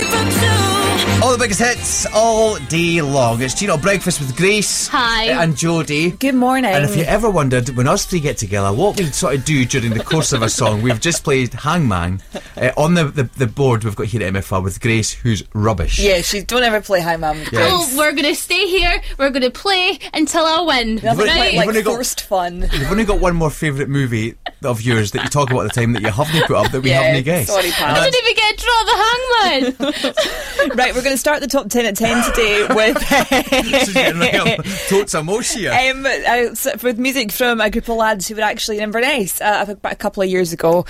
When the music plays so do the presenters!!